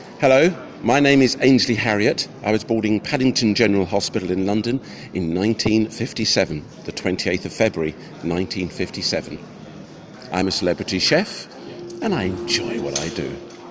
The speaking voice of English chef and television presenter Ainsley Harriott
Ainsley_Harriott_voice.flac